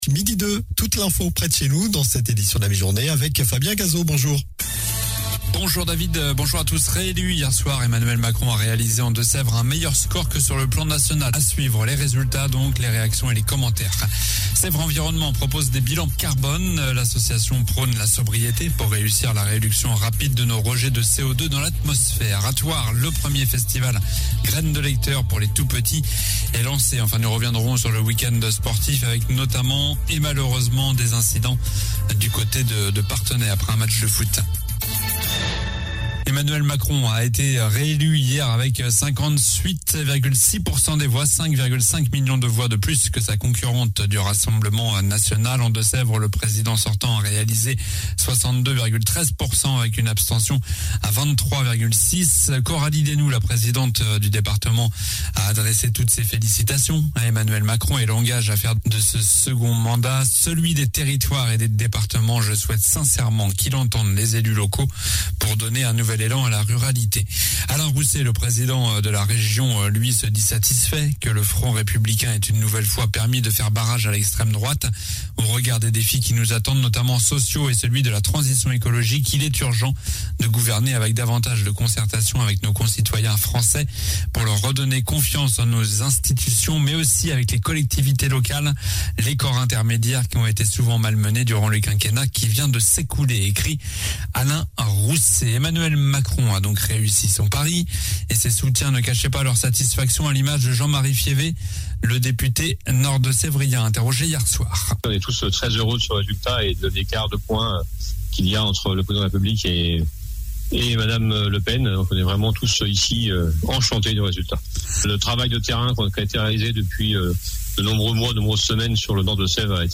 Journal du lundi 25 avril (midi)